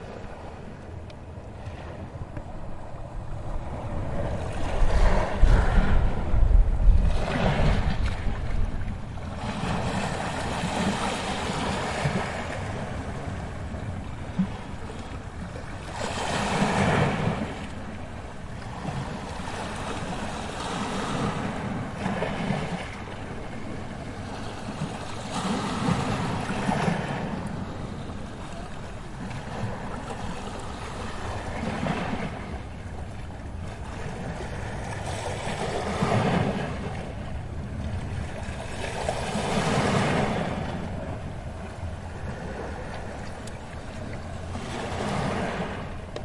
气氛 " 海洋与船
描述：有岩石和汽艇的轻的海在背景中。
Tag: 沙滩 海岸 海浪 摩托艇 海洋 海鸥 海鸥